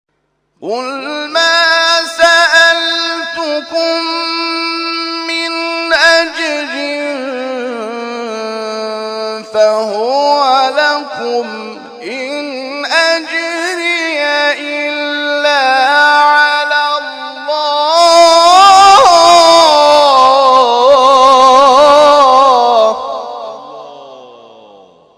گروه جلسات و محافل ــ محفل انس با قرآن این هفته آستان عبدالعظیم الحسنی(ع) با تلاوت قاریان ممتاز و بین‌المللی کشورمان برگزار شد.
در ادامه تلاوت‌های این محفل ارائه می‌شود.